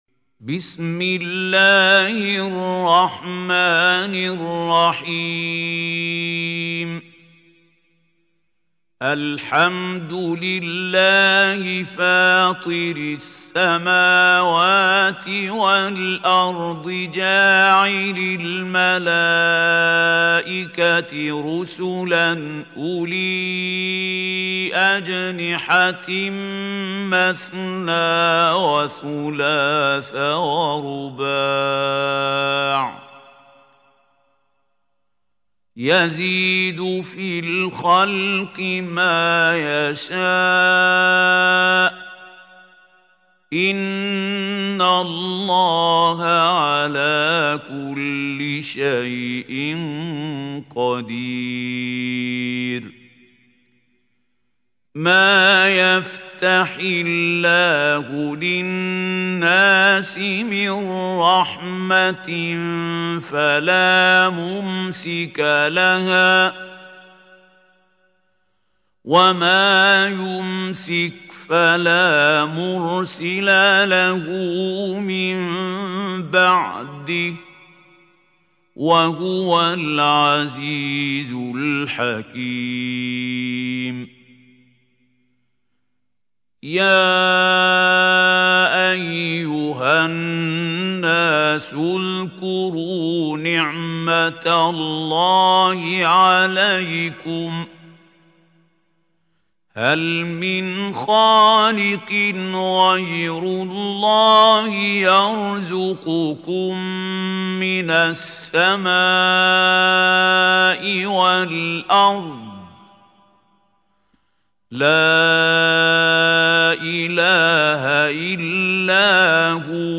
سورة فاطر | القارئ محمود خليل الحصري